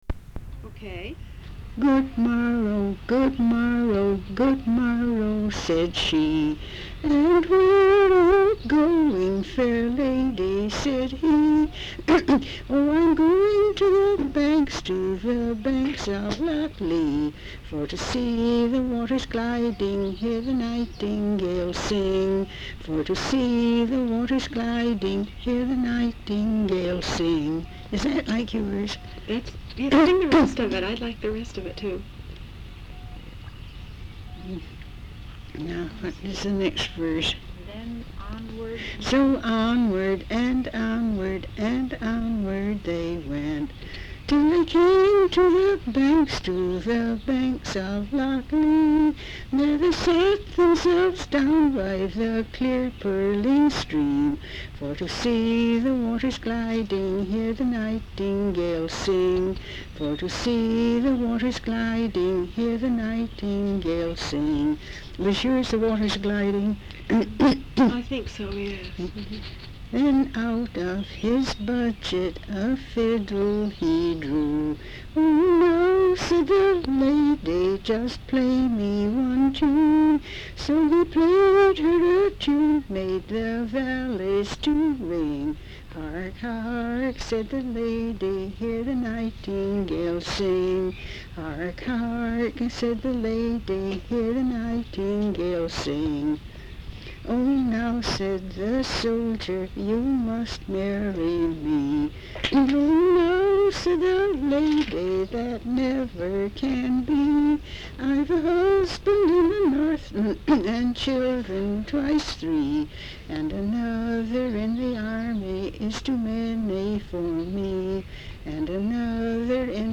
Folk songs, English--Vermont
sound tape reel (analog)
Location Putney, Vermont